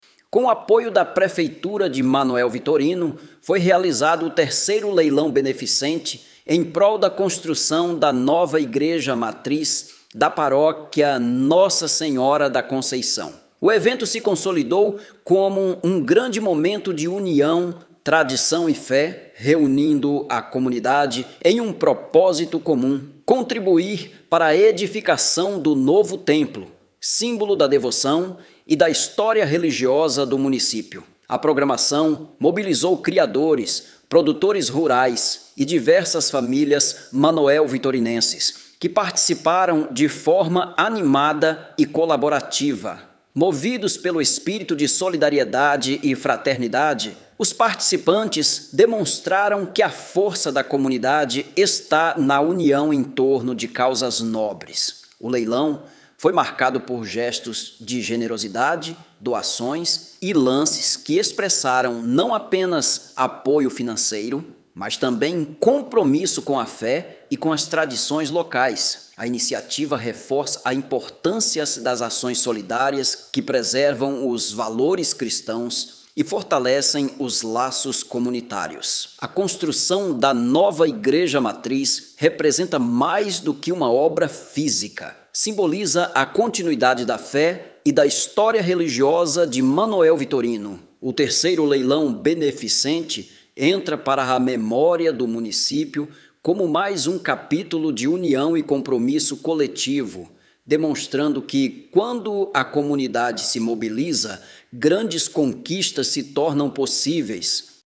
Voz-OFFs.mp3